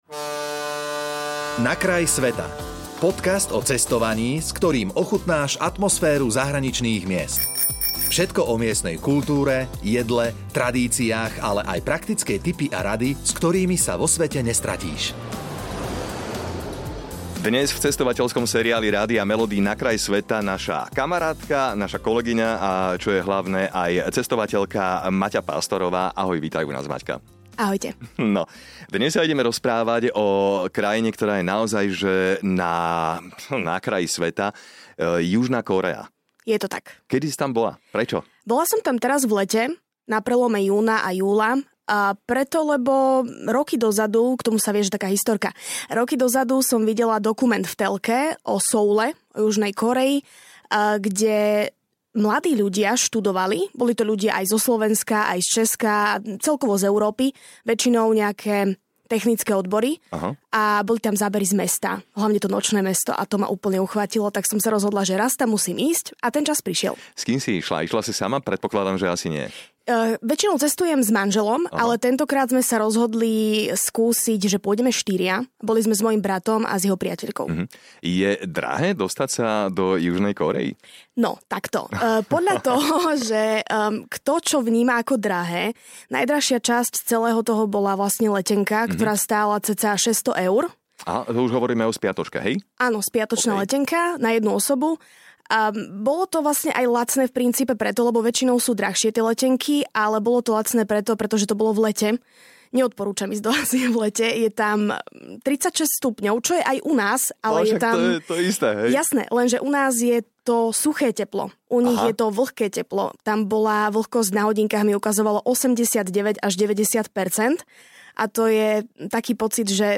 V zaujímavom rozhovore sa dozviete, koľko približne stojí spiatočná letenka a ktorý typ dopravy sa v tejto krajina oplatí využiť. Priblíži tiež, aké sú kórejské letá, čo očakávať od miestnej kuchyne a čo by sa naše krajiny mohli od seba navzájom naučiť.